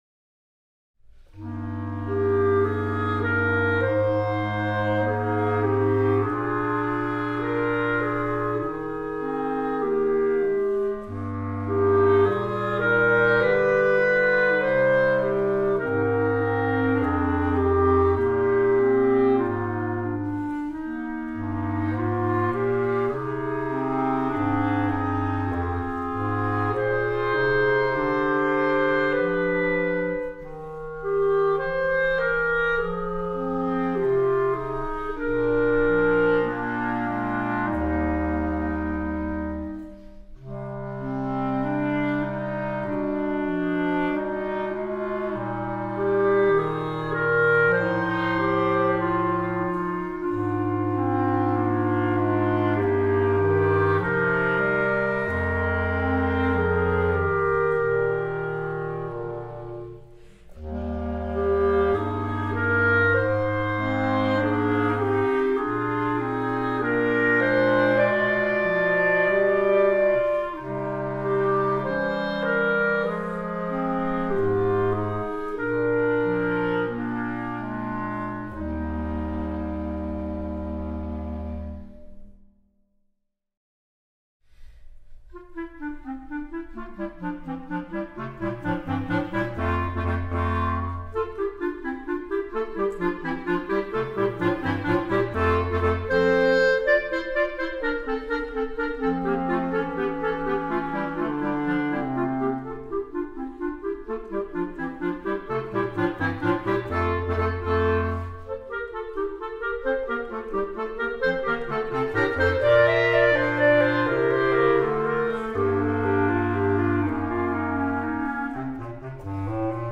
B♭ Clarinet 1 B♭ Clarinet 2 B♭ Clarinet 3 Bass Clarinet
单簧管四重奏
这是一部由三个乐章组成的作品，将亲切悦耳的旋律紧凑地融为一体。